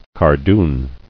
[car·doon]